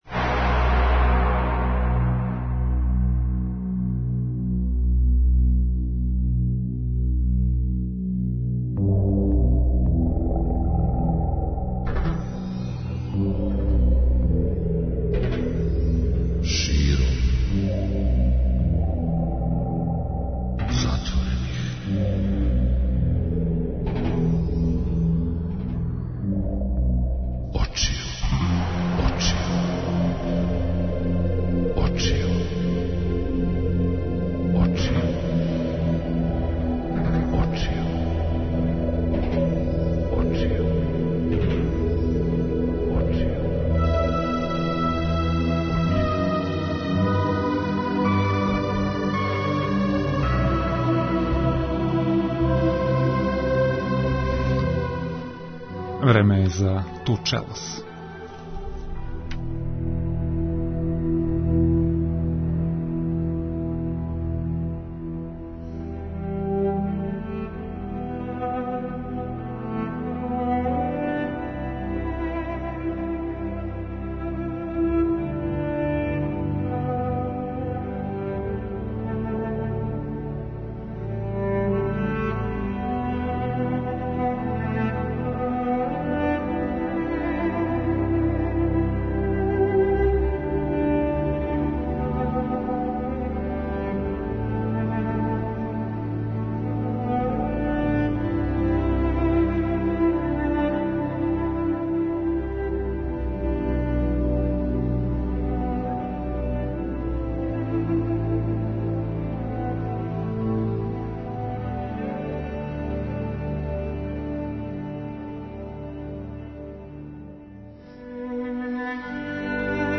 А са недавно одржаног Фестивала епске фантастике, чућете неке занимљивости из живота славног писца Џ.Р.Р. Толкина - са предавања које су одржали